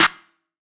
SOUTHSIDE_snare_crisppy.wav